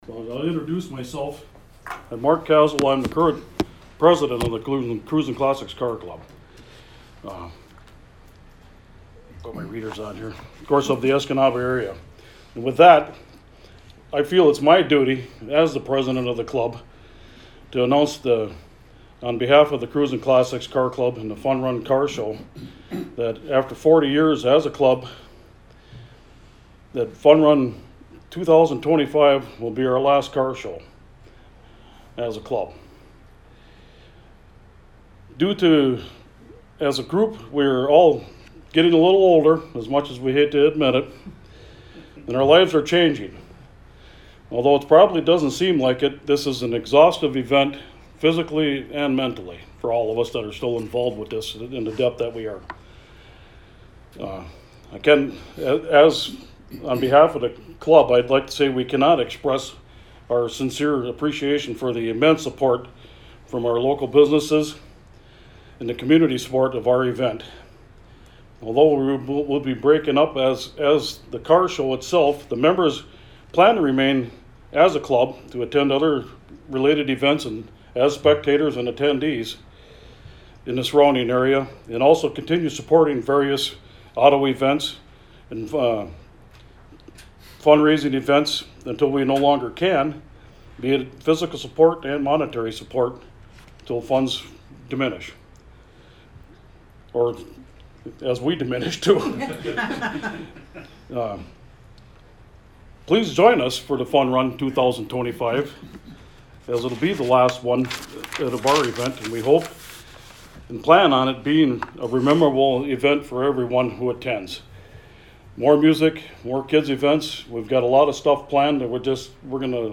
CLICK BELOW TO LISTEN TO TUESDAY’S PRESS CONFERENCE